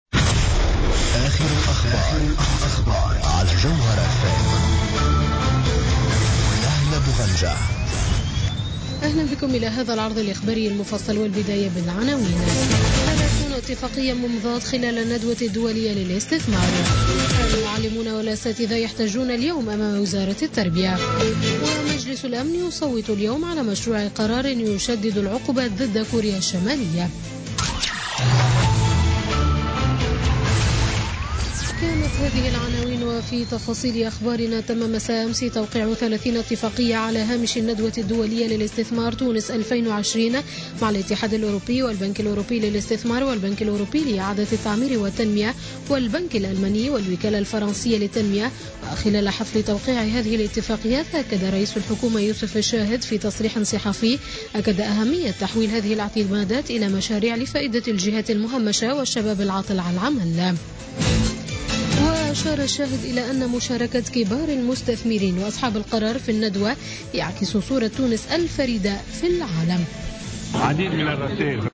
نشرة أخبار منتصف الليل ليوم الاربعاء 30 نوفمبر 2016